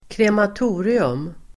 Ladda ner uttalet
krematorium substantiv, crematorium Uttal: [kremat'o:rium] Böjningar: krematoriet, krematorier Definition: byggnad för kremering crematorium substantiv, krematorium Böjningar: crematoria [plural] Förklaring: byggnad för kremering